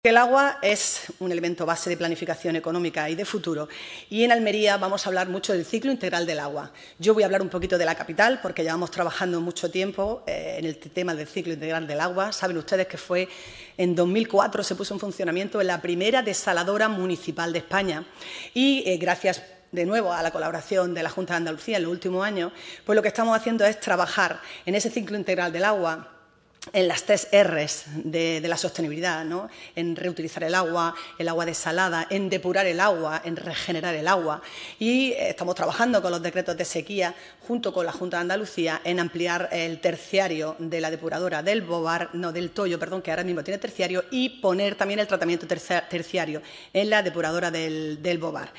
Esta cita que se ha celebrado en el Teatro Apolo de Almería ha sido inaugurada por la alcaldesa de Almería, María del Mar Vázquez, el presidente de Diputación, Javier A. García, así como por el consejero de Agricultura, Pesca, Agua y Desarrollo Rural, Ramón Fernández-Pacheco.